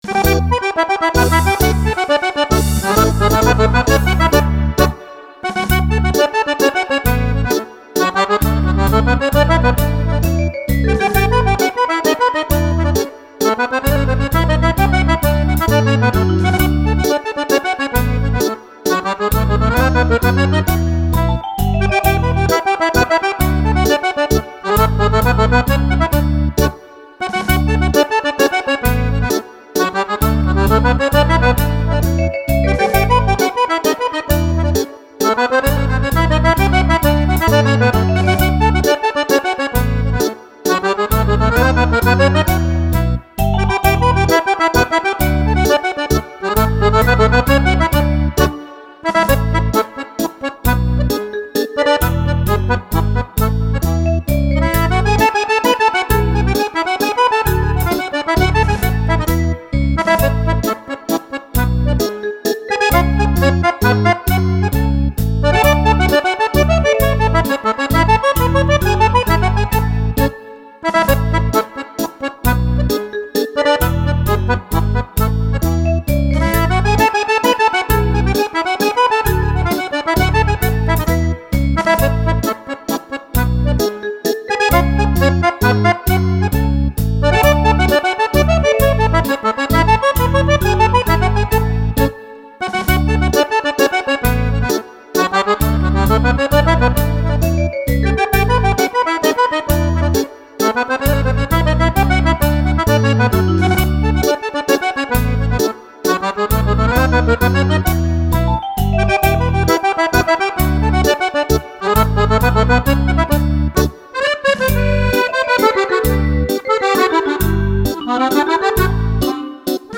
Mazurka
Antologia di 51 successi per fisarmonica